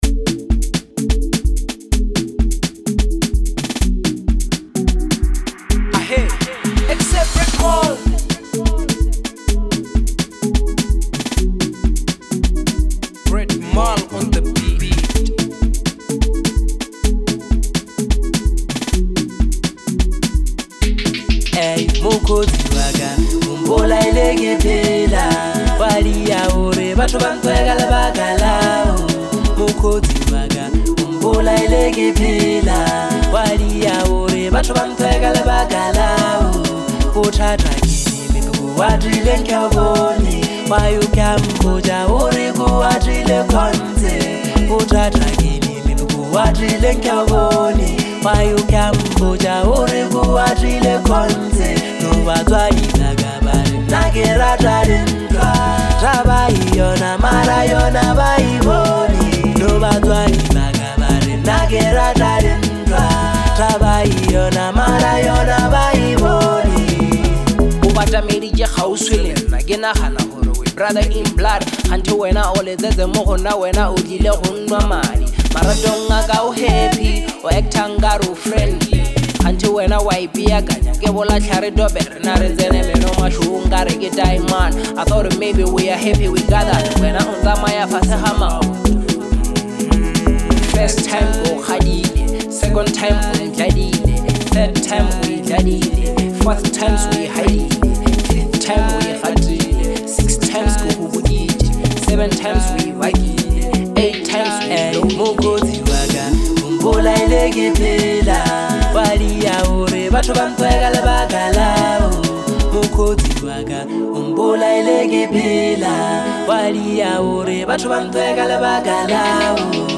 04:22 Genre : Bolo House Size